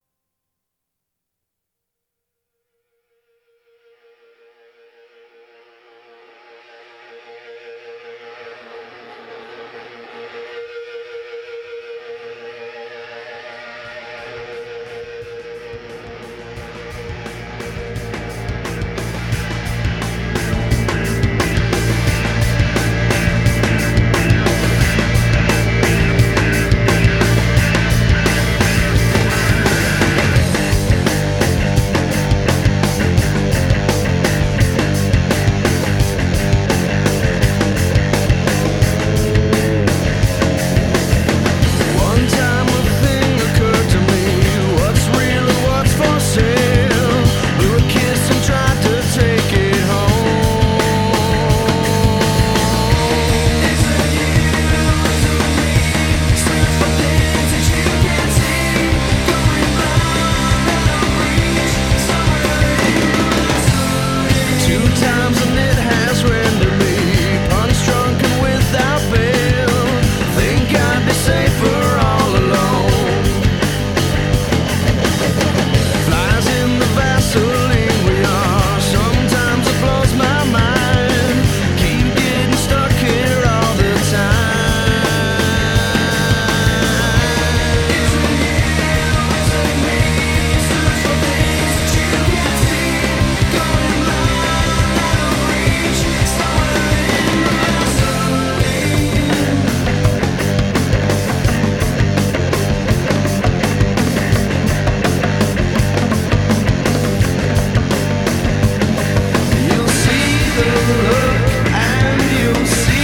融入爵士、藍調、放克等不同風格